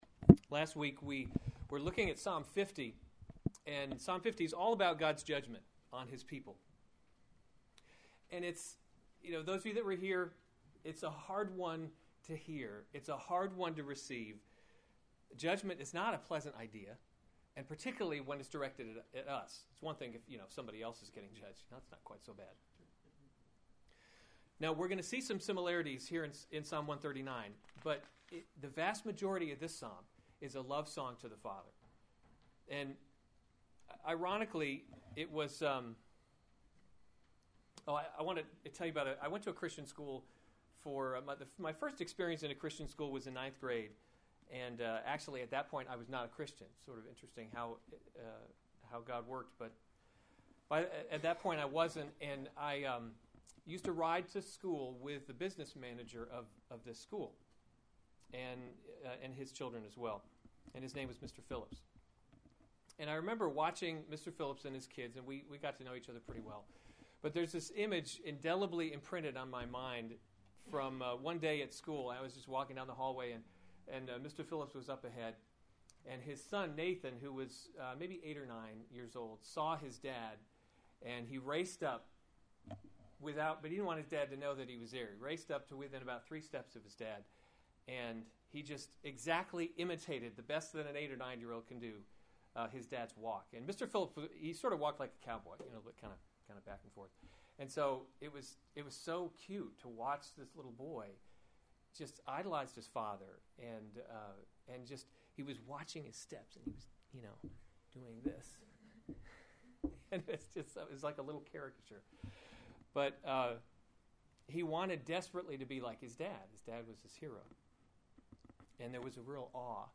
August 8, 2015 Psalms – Summer Series series Weekly Sunday Service Save/Download this sermon Psalm 139 Other sermons from Psalm Search Me, O God, and Know My Heart To the […]